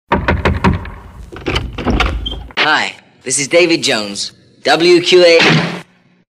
Davy Jones drop in (Door slams)